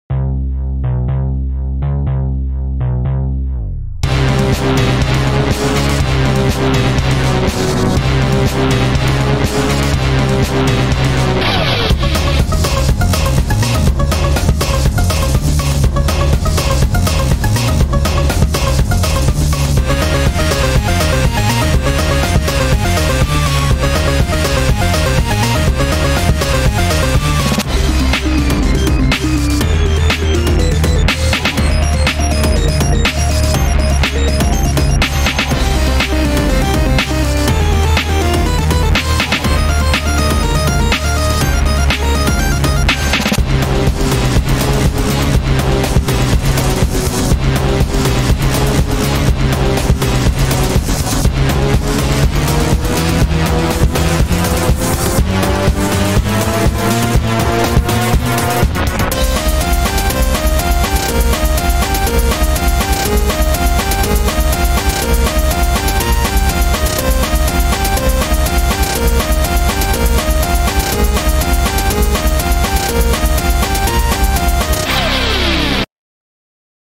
Chase Theme OST